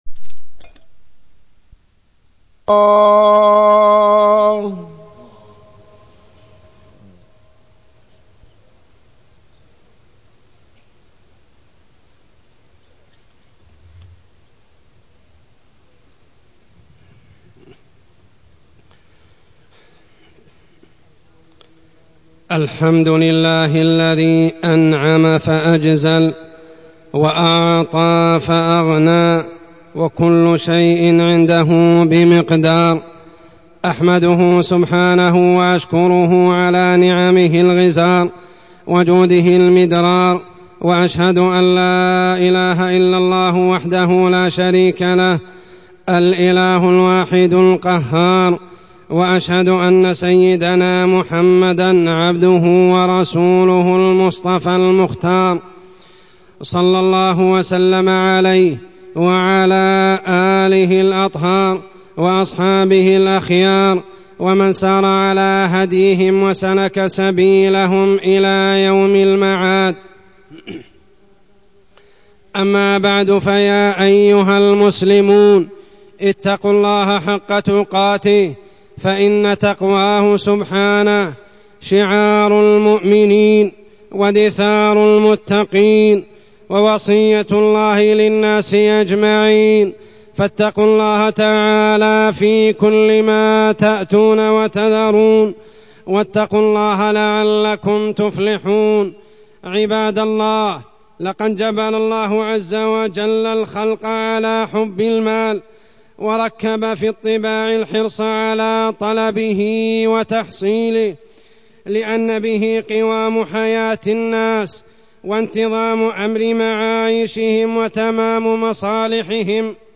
تاريخ النشر ١٢ محرم ١٤٢٢ هـ المكان: المسجد الحرام الشيخ: عمر السبيل عمر السبيل إكتساب المال وضوابطه